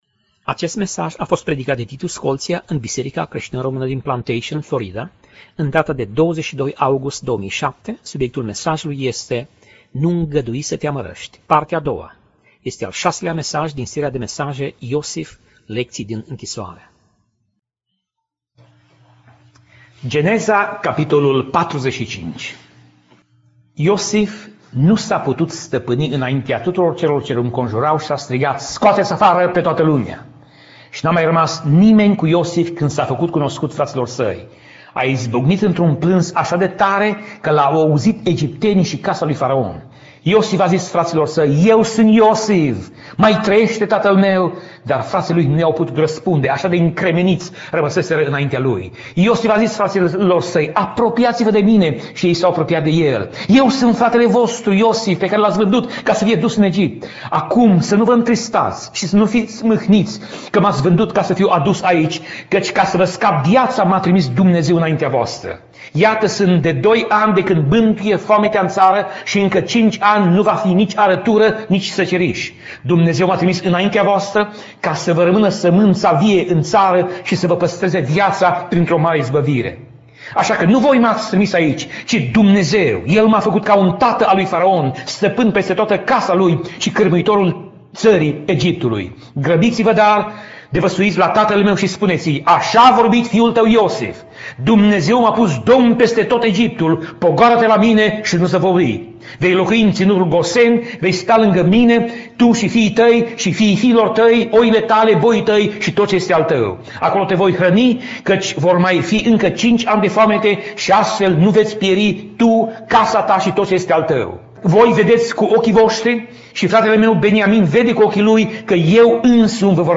Pasaj Biblie: Geneza 45:1 - Geneza 45:28 Tip Mesaj: Predica